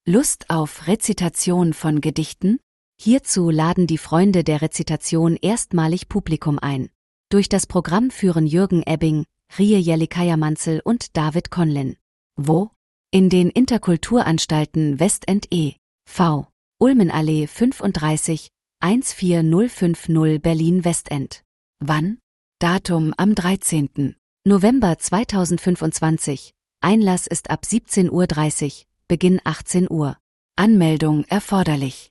Dabei geht es mal lustig, mal laut - dann leise, mal zart, oder auch traurig zu.
Lust-auf-Rezitation.mp3